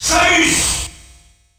The announcer saying Samus' name in French releases of Super Smash Bros.
Samus_French_Announcer_SSB.wav